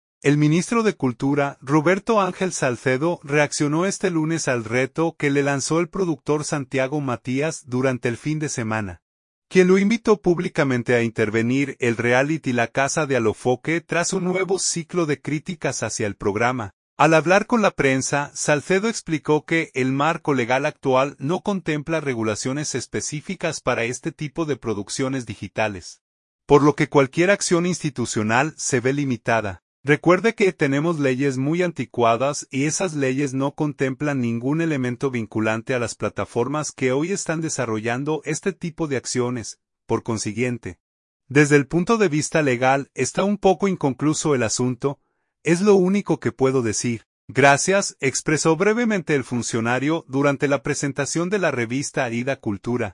Al hablar con la prensa, Salcedo explicó que el marco legal actual no contempla regulaciones específicas para este tipo de producciones digitales, por lo que cualquier acción institucional se ve limitada.